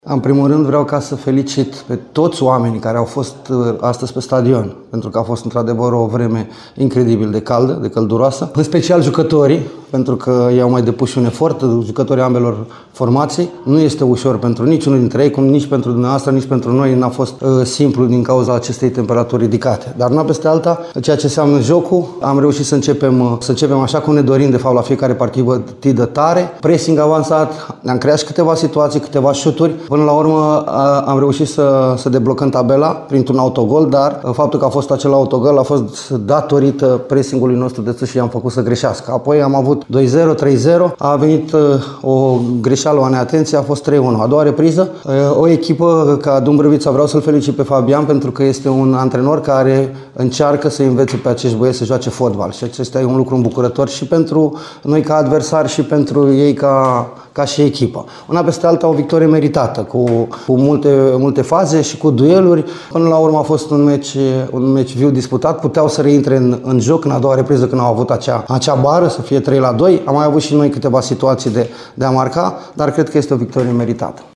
Tehnicianul Reșiței, Flavius Stoican, se bucură de trei puncte obținute în deplasare și pe o căldură infernală: